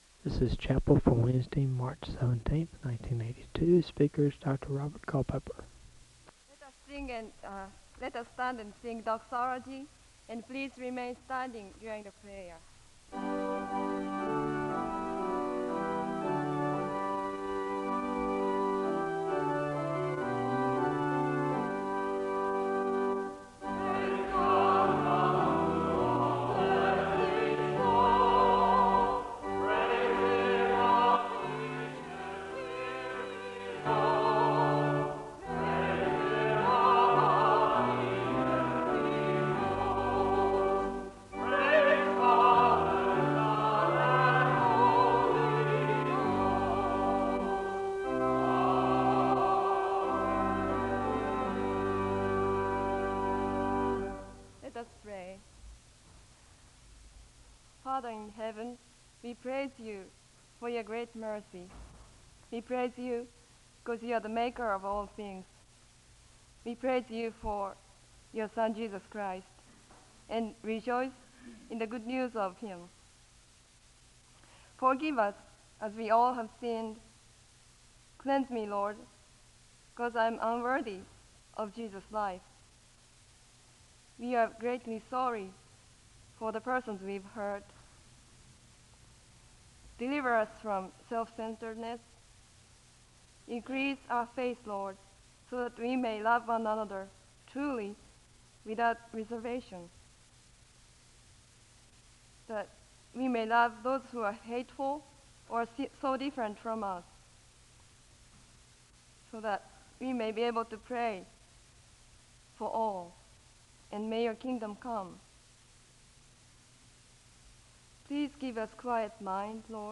The service begins with the singing of the Doxology and a word of prayer (00:00-02:29). The speaker reads from Luke 24:45-49 (02:30-03:59). There is a presentation of a song of worship (04:00-09:00).
SEBTS Chapel and Special Event Recordings SEBTS Chapel and Special Event Recordings